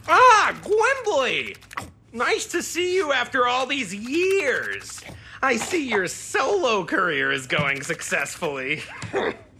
Cartoon_Male_Crimson.wav